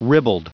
.adjective.(pronounced 'rib uld')